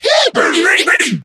mech_mike_start_vo_02.ogg